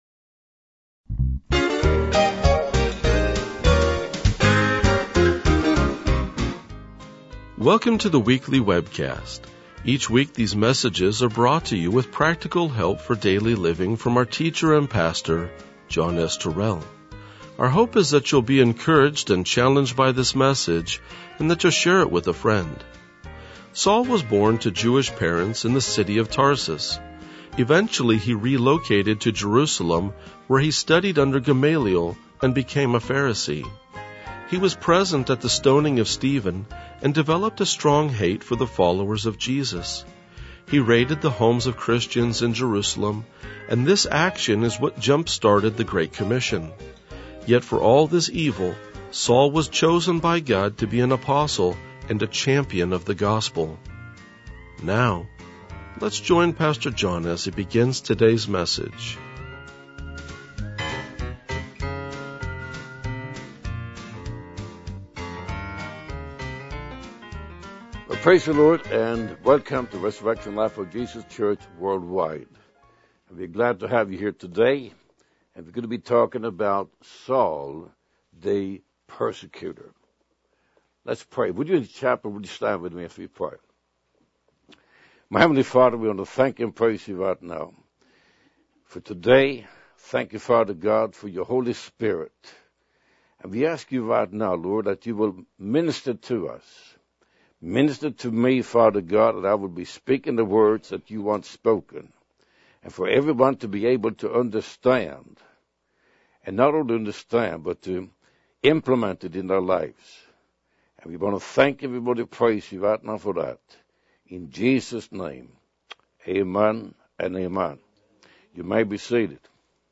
RLJ-2005-Sermon.mp3